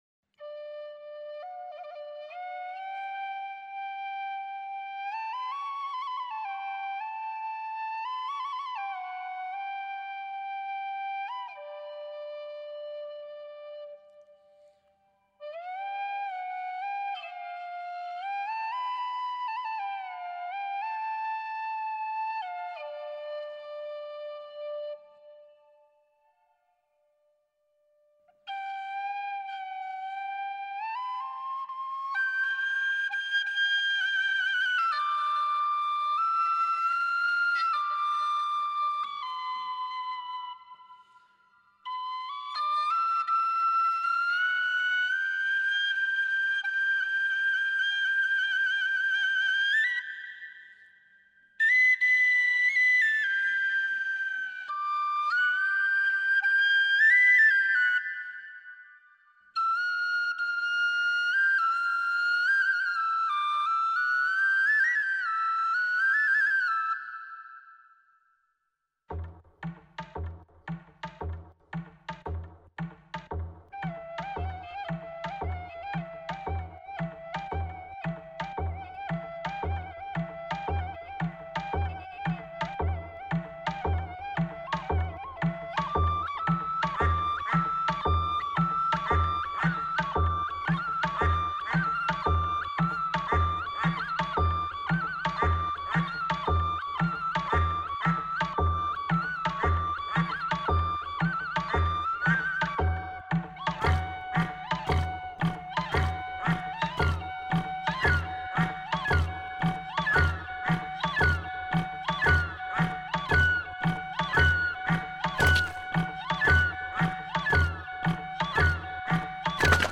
中阮、竖笛、打击乐、电贝司、弦子、男声
录音地点：上海广播大厦一号录音棚